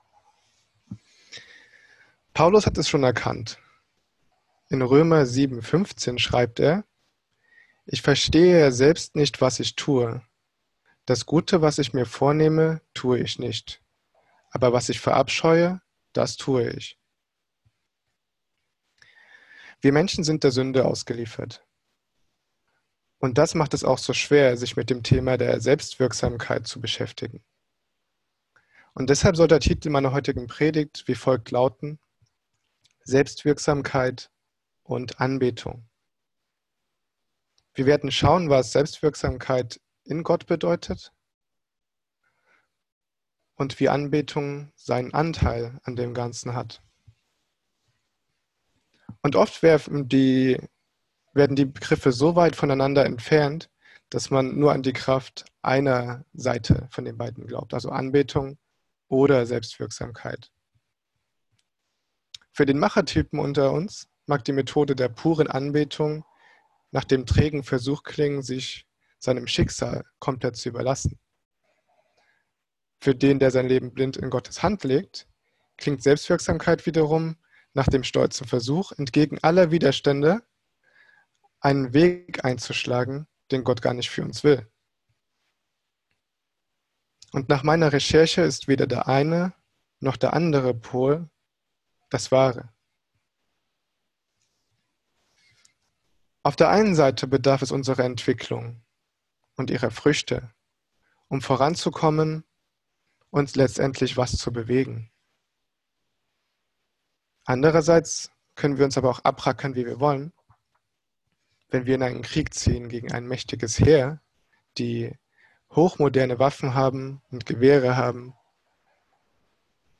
Sonntagspredigt